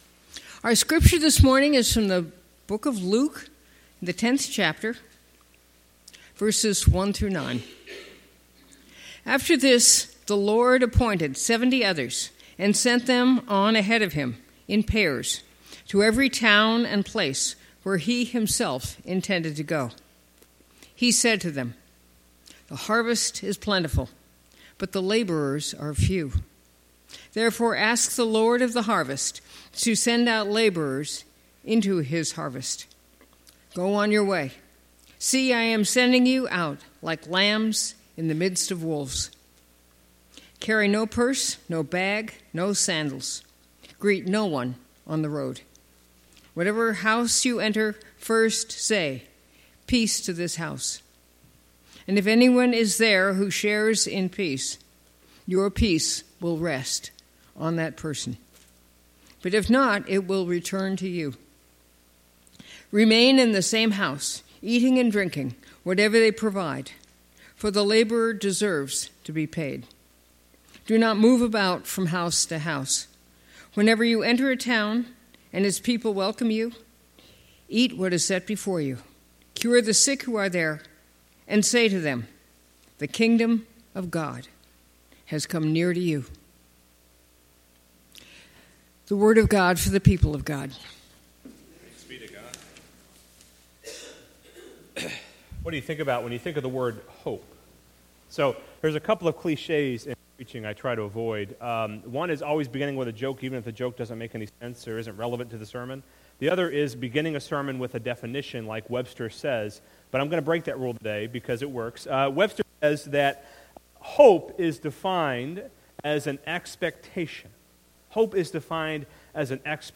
Passage: Luke 10:1-9 Service Type: Sunday Morning